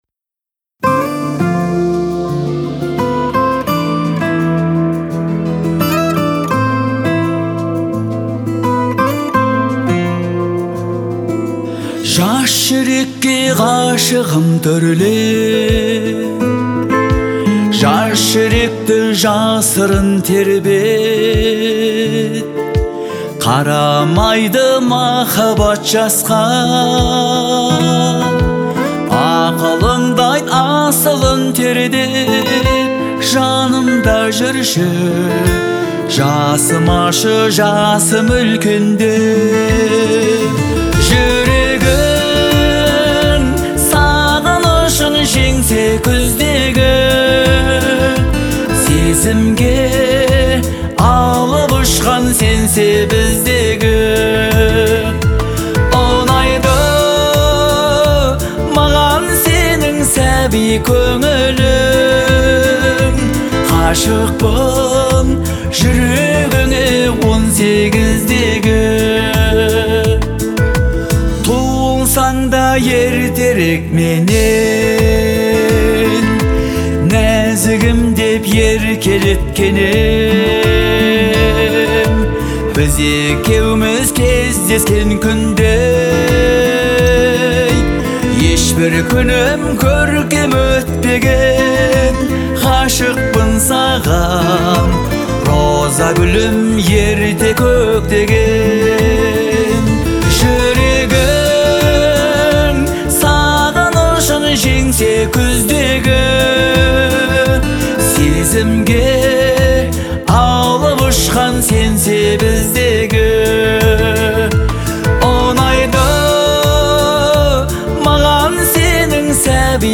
это романтическая песня в жанре казахской поп-музыки